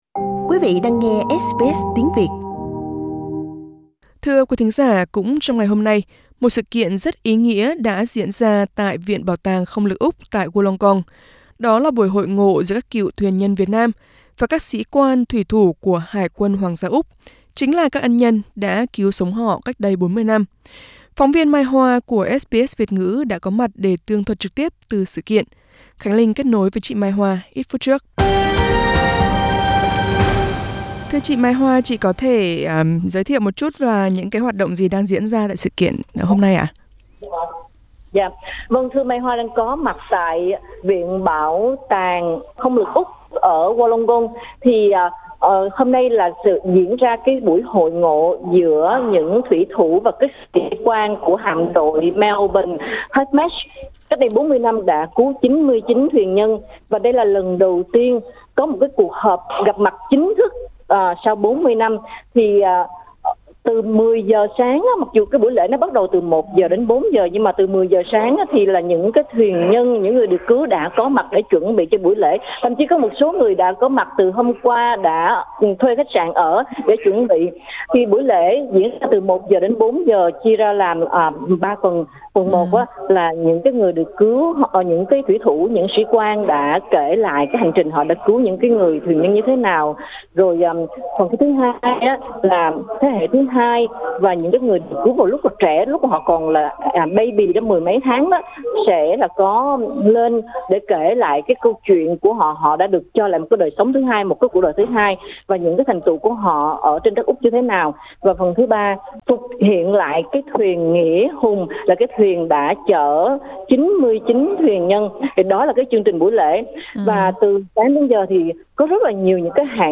Trong ngày Chủ nhật 20/6, một sự kiện rất ý nghĩa diễn ra tại Viện bảo tàng Không Lực Úc tại Wollongong. Các cựu thuyền nhân Việt Nam và các sĩ quan, thủy thủ của hải Quân hoàng Gia Úc có cuộc gặp mặt chính thức, 40 năm sau cuộc giải cứu trên Biển đông.